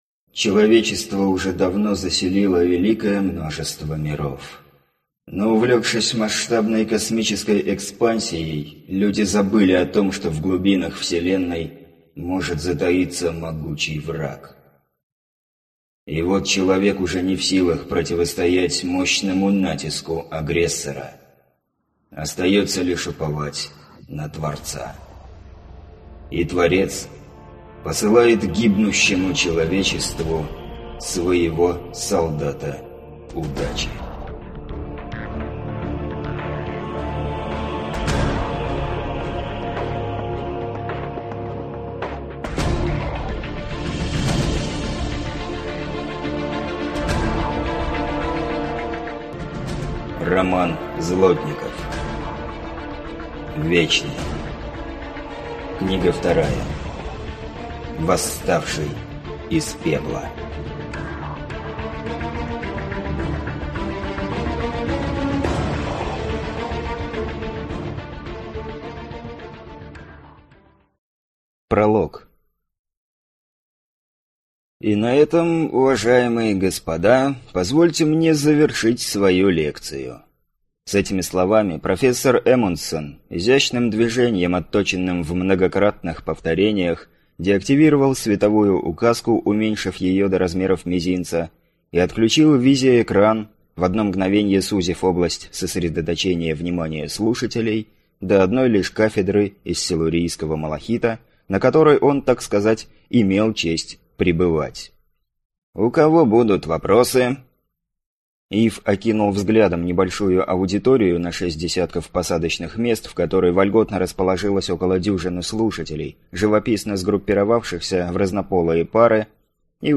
Аудиокнига Вечный. Восставший из пепла | Библиотека аудиокниг